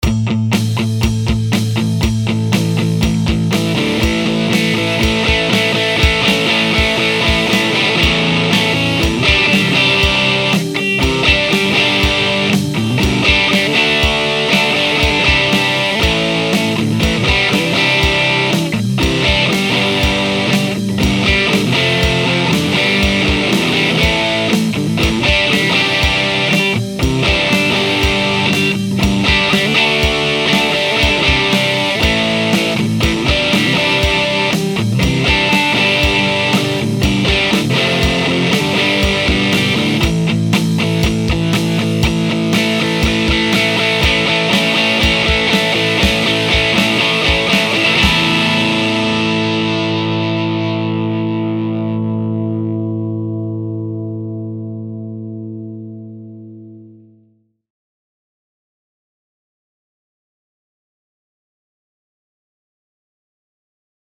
That produces some serious grind, but as you’ll hear in the clip, there’s very little compression.
The drive tone the VRX18 produces is absolutely magnificent! When I recorded the clip, I couldn’t believe all the overtones and subtle harmonics that the amp was producing!
In addition to the amp, I played through Goldie, running her straight into the amp, then through the insanely transparent PRX150-Pro attenuator set at variable attenuation mode at about 3 o’clock, then into a custom Aracom 1 X 12.
vrx18-drive.mp3